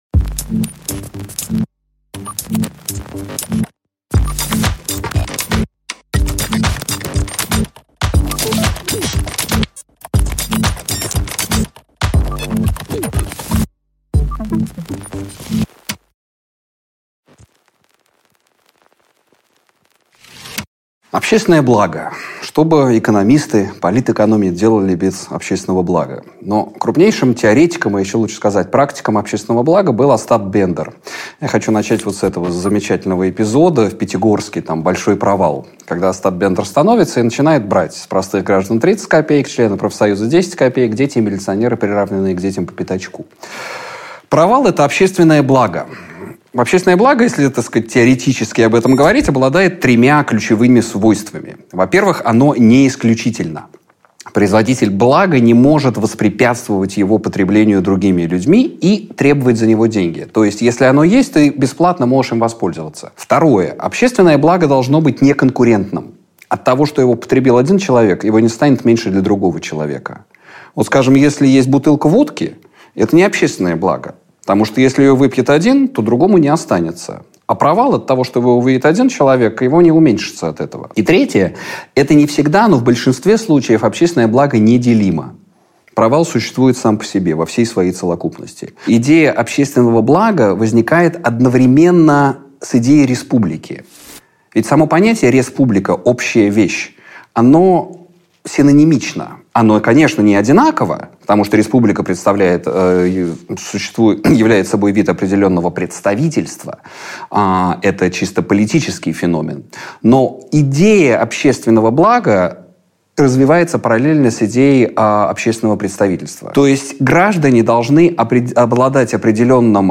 Аудиокнига Коммунистический реванш | Библиотека аудиокниг